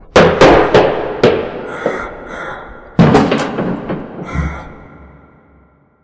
scream_6.wav